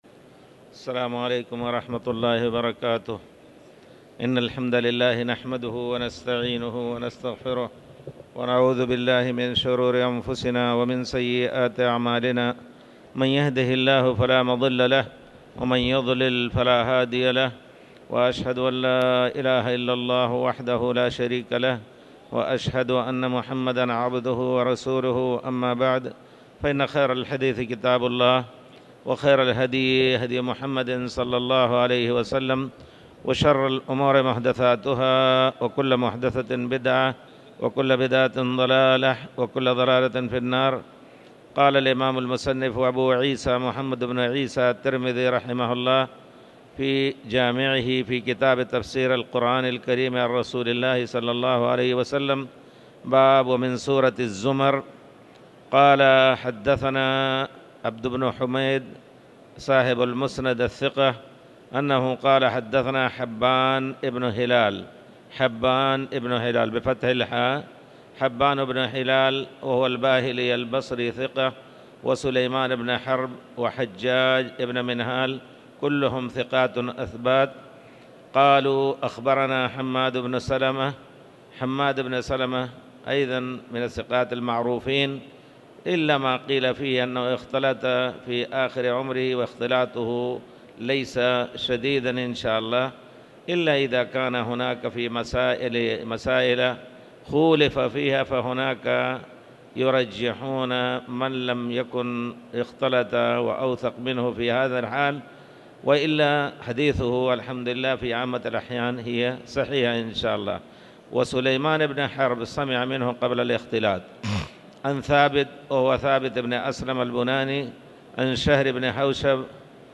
تاريخ النشر ١٢ ربيع الثاني ١٤٤٠ هـ المكان: المسجد الحرام الشيخ